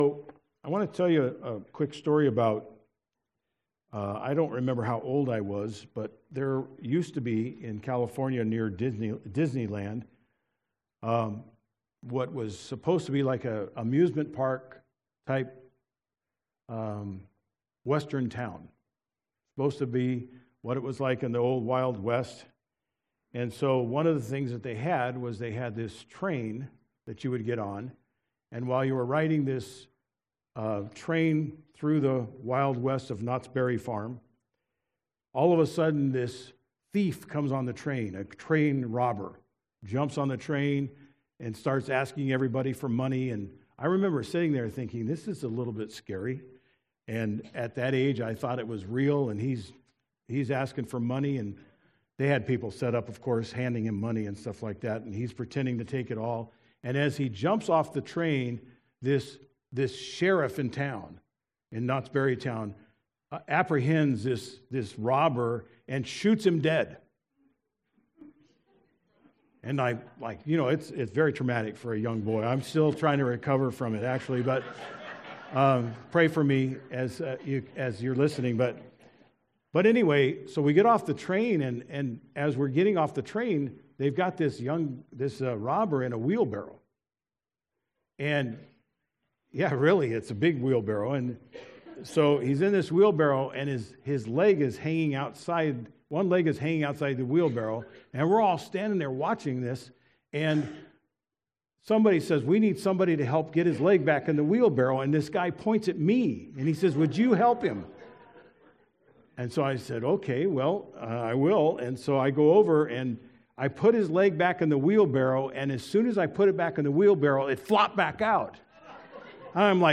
Sermon-03-10-24-DD.mp3